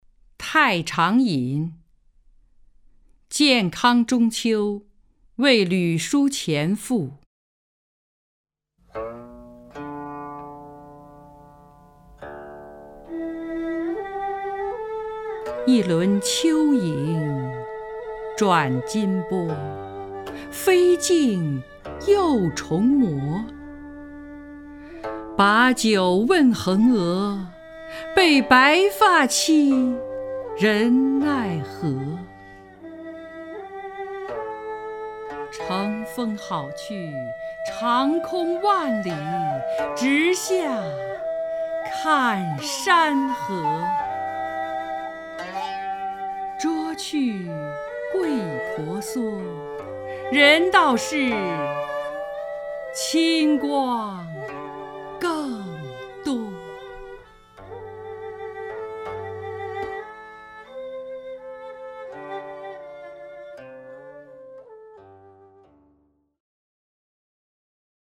张筠英朗诵：《太常引·建康中秋夜为吕叔潜赋》(（南宋）辛弃疾)
名家朗诵欣赏 张筠英 目录